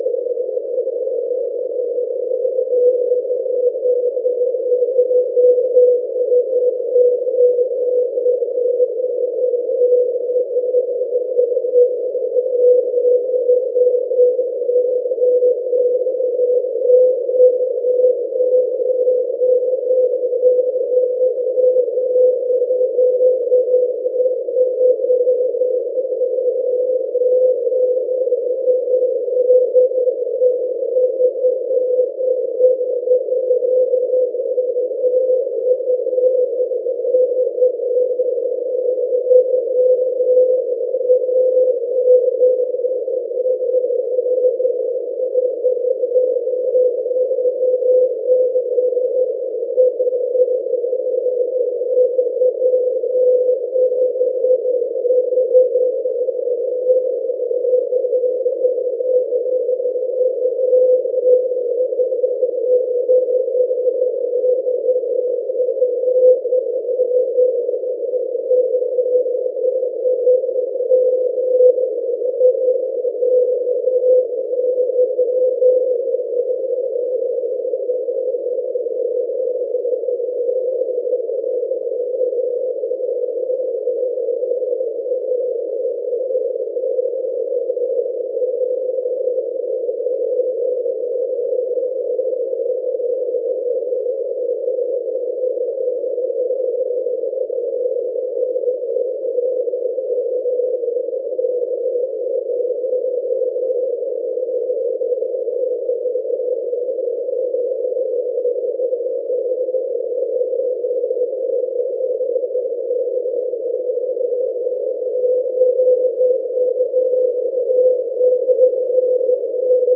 I have not before seen rain scatter be so narrow and clear.